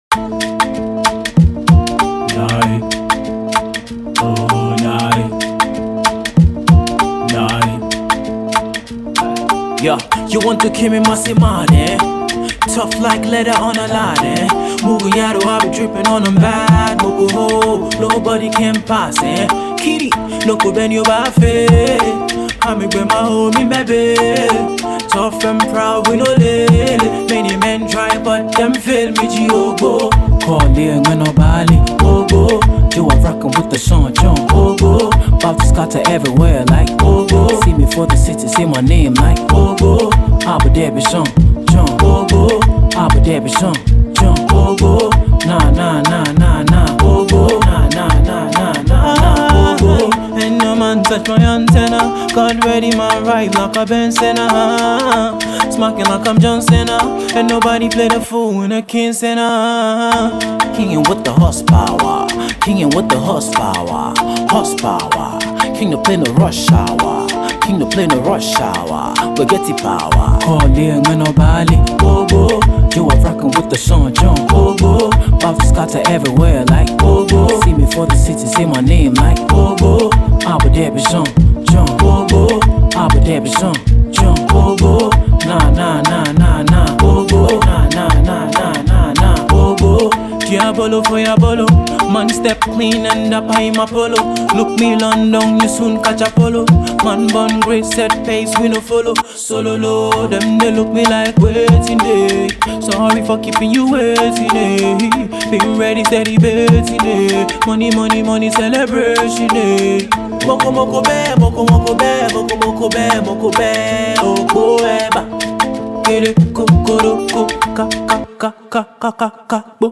Afrobeat
Melodious Vocals
Laid Back